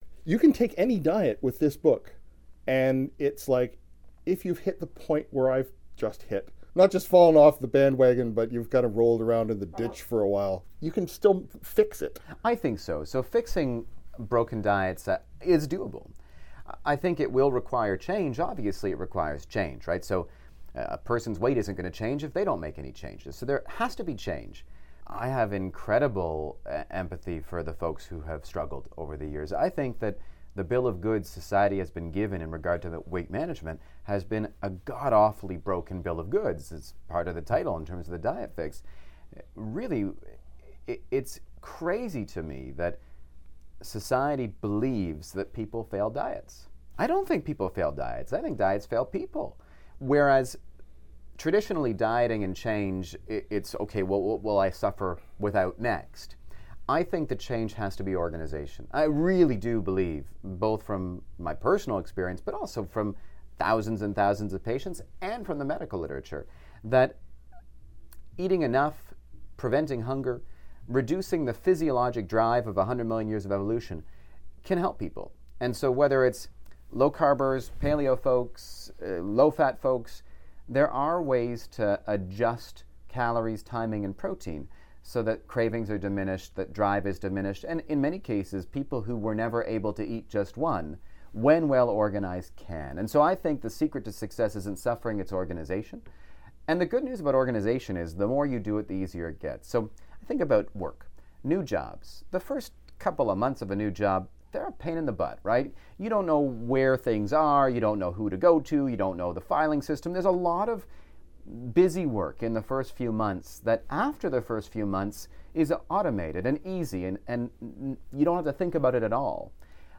Type: Interview